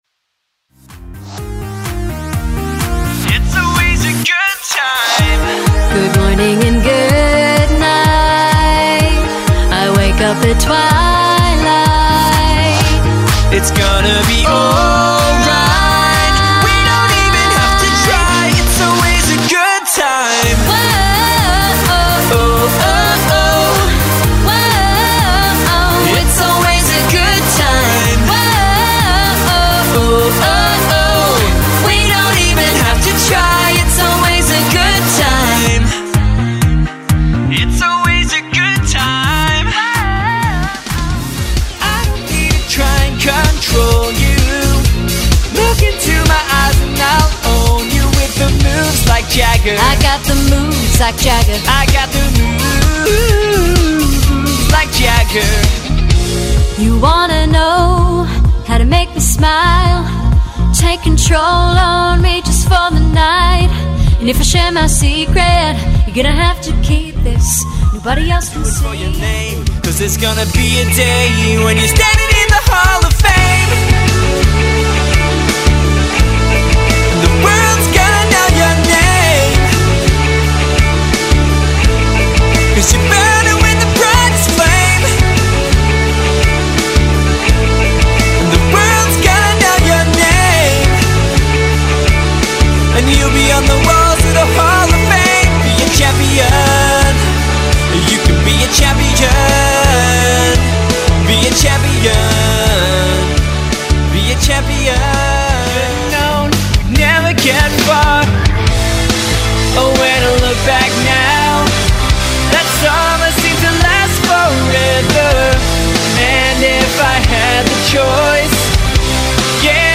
is the hottest new party rock band to hit the circuit.